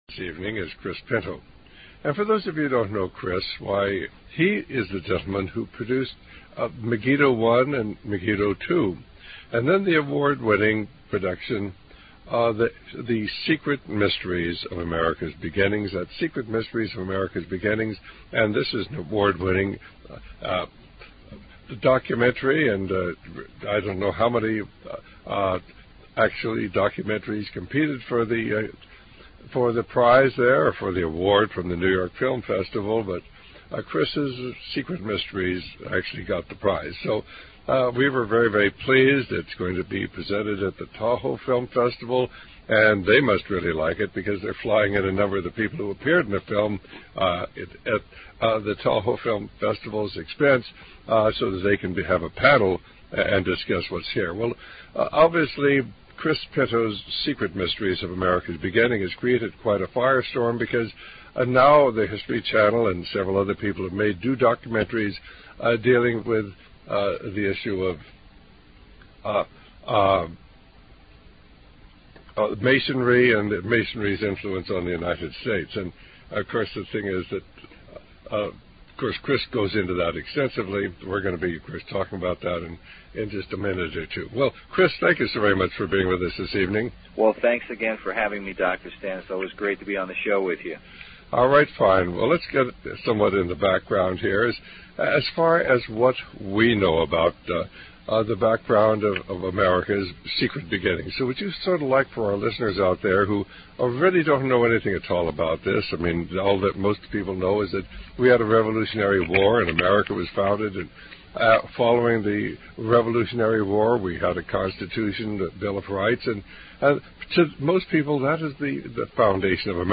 Distorted audio